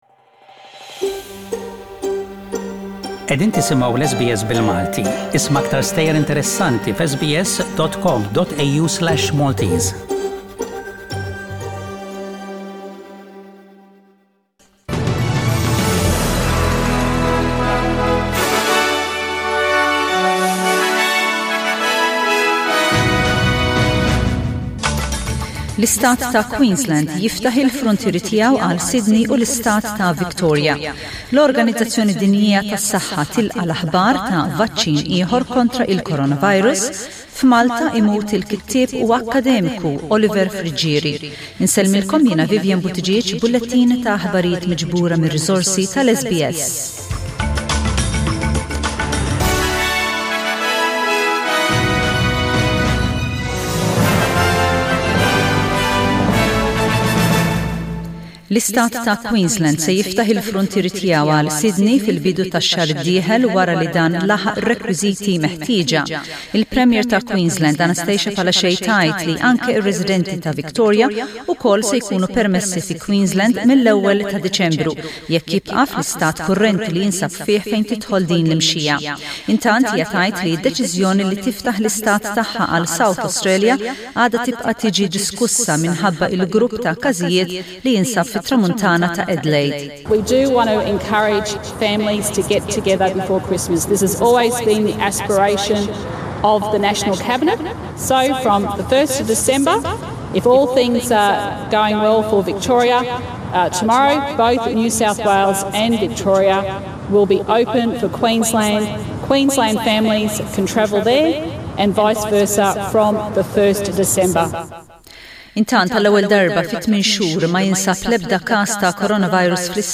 SBS Radio | News in Maltese: 24/11/20